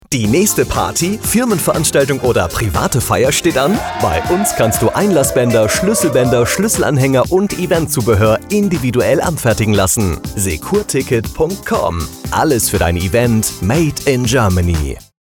Funkspot_Securticket-GmbH-15-Sek-1.mp3